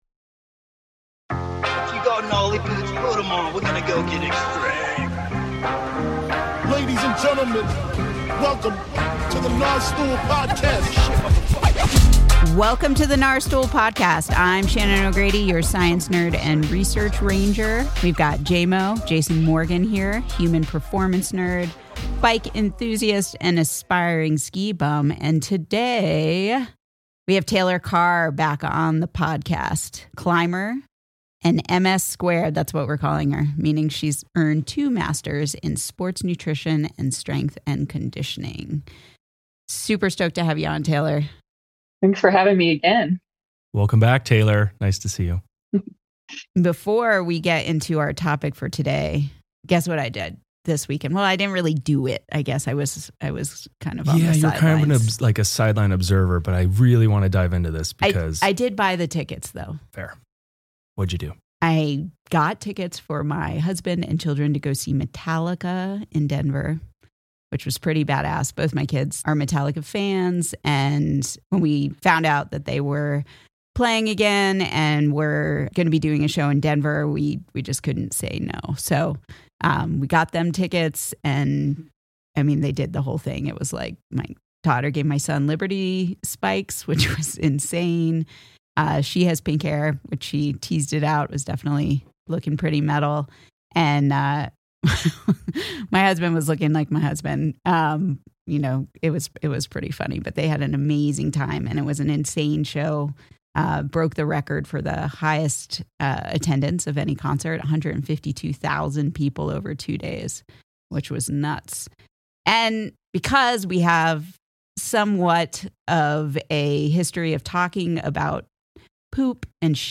From amino acid profiles to the difference between complete and incomplete proteins, the trio unpacks the science behind this essential macronutrient—and why it’s at the center of so many nutrition conversations right now.